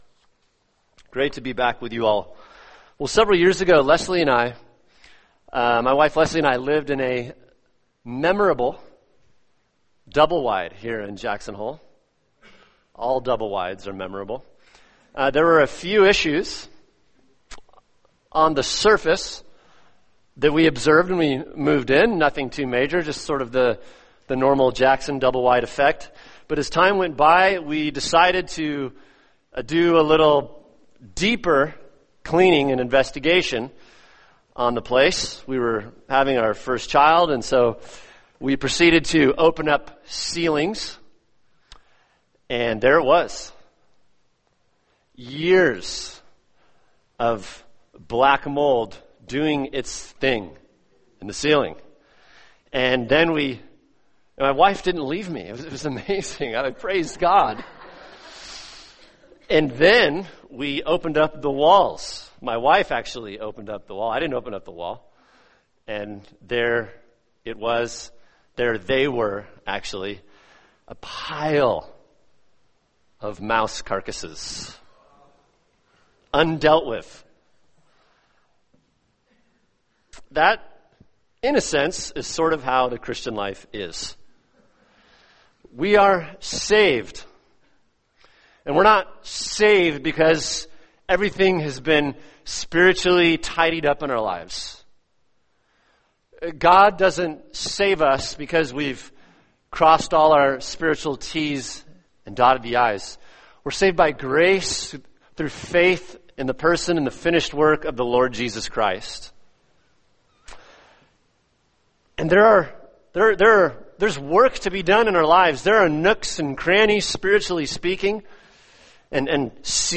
[sermon] Romans 7:14-25 Every Christian’s Battle | Cornerstone Church - Jackson Hole